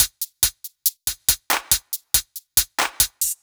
Index of /musicradar/french-house-chillout-samples/140bpm/Beats
FHC_BeatA_140-01_HatClap.wav